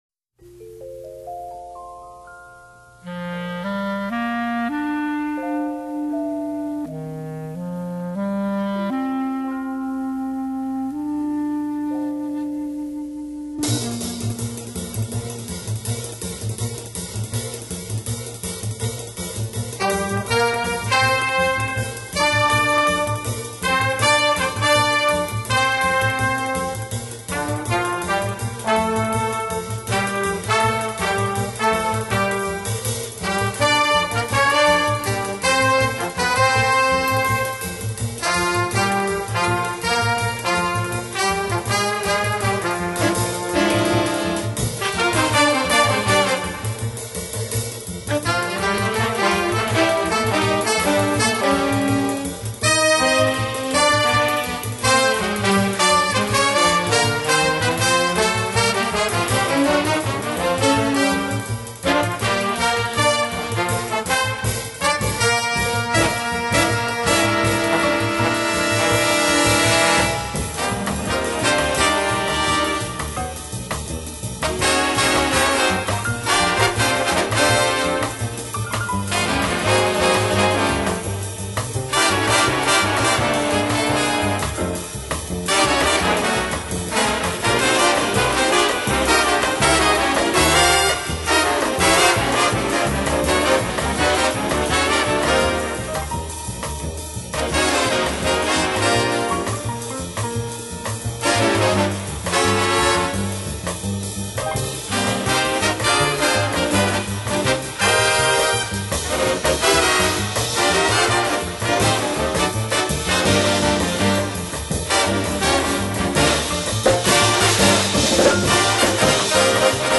爵士黑管大師
Jazz | EAC (APE+CUE+LOG) | Full 300dpi scans | 1,363 GB